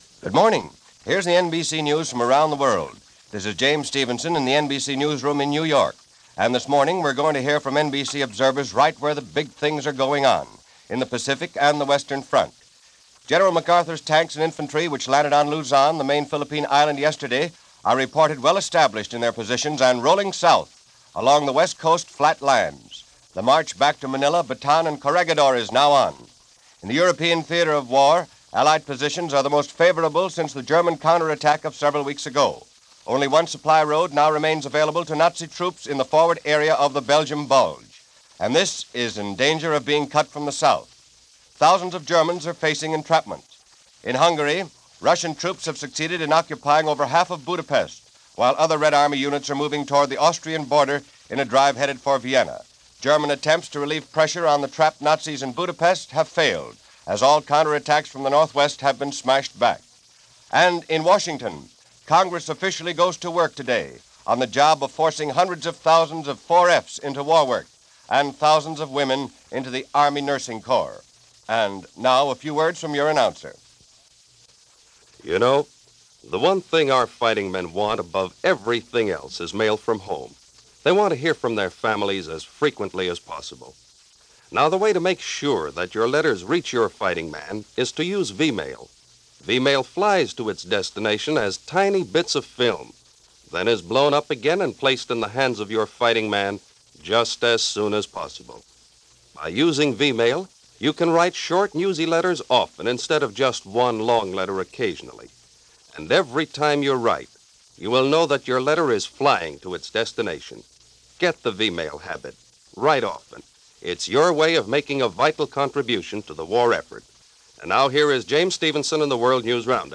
Red Army Races To Vienna - The March To Manila - Germans Face Entrapment - January 10, 1945 - News of the war from NBC.